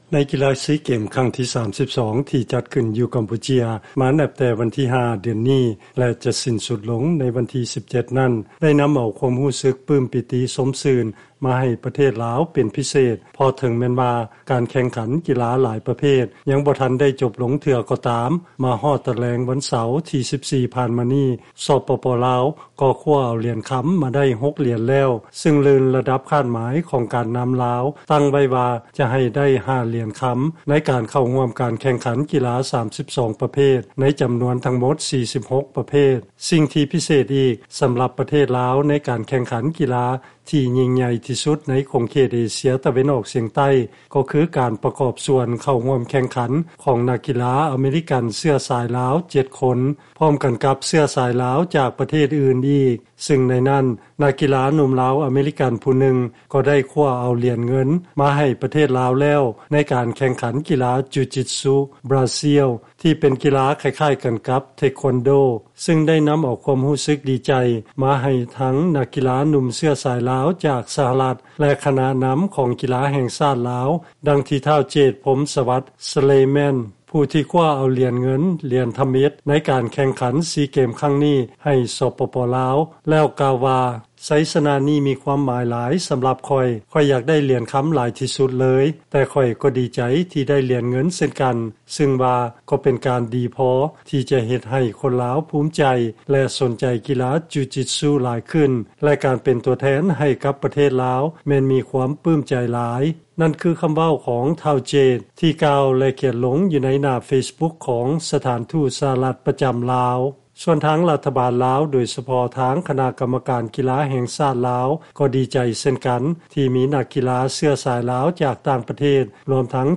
ຟັງລາຍງານ ນັກກິລາ ລາວ ແລະ ອາເມຣິກັນເຊື້ອສາຍລາວ ຮ່ວມກັນລົງແຂ່ງຂັນ ໃນຊີເກມ ຄັ້ງທີ 23 ຊ່ອຍຄວ້າຫຼຽນເງິນ ທຳອິດມາໄດ້